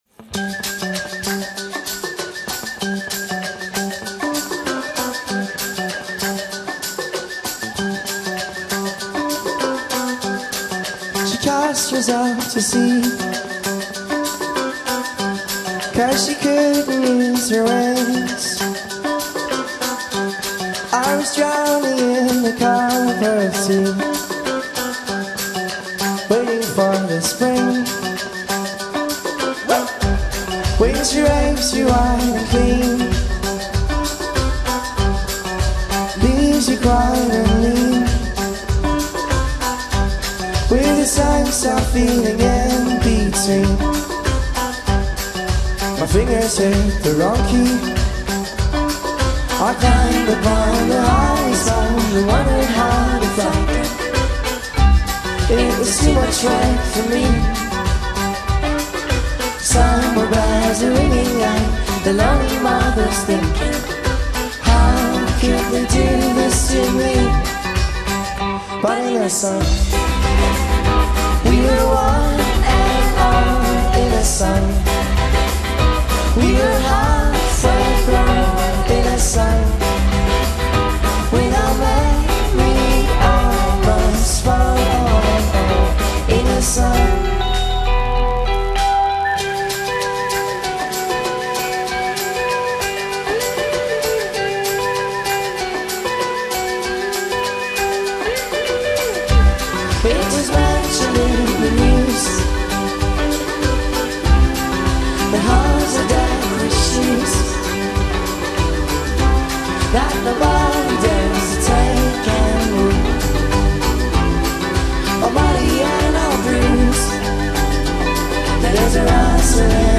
Still a very engaging and inventive band.